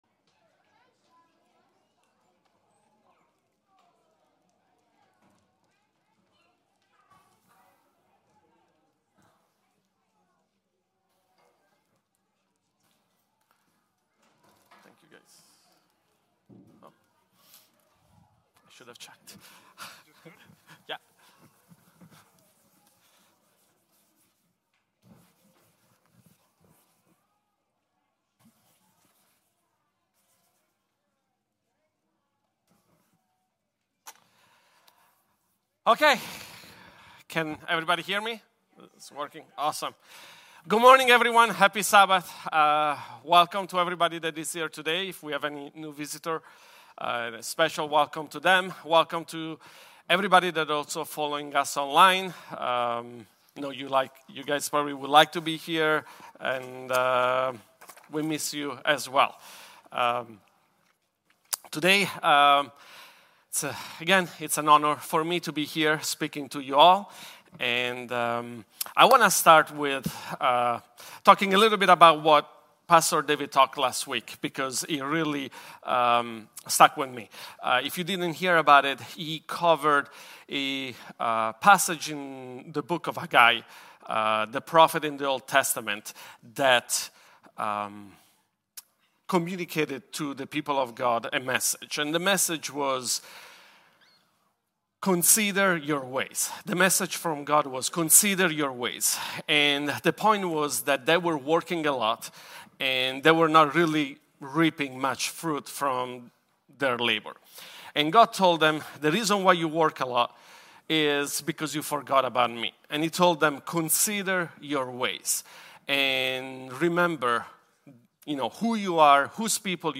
Love Covers A Multitude of Sins In this sermon, we explore Peter’s call for believers to extend sincere, forgiving love to one another.